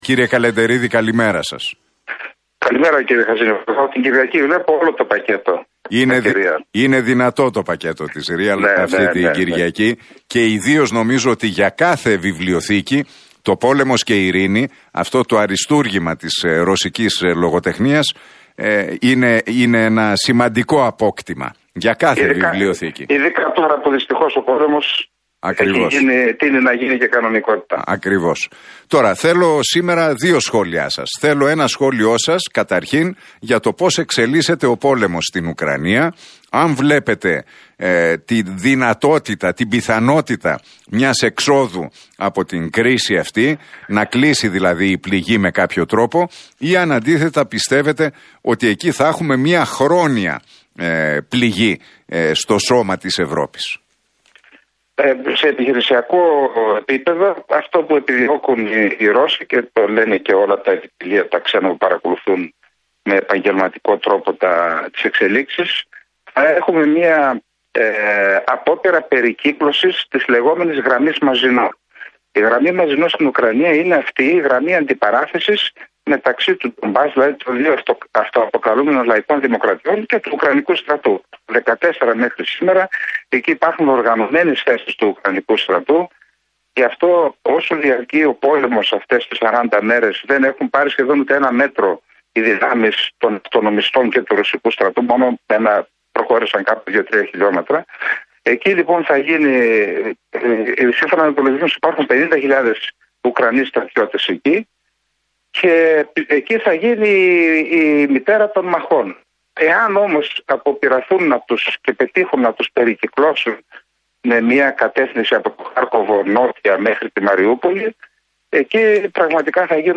Ο γεωπολιτικός αναλυτής, συγγραφέας και αρθρογράφος, Σάββας Καλεντερίδης, μιλώντας στον Realfm 97,8 και την εκπομπή του Νίκου Χατζηνικολάου δήλωσε ότι "σε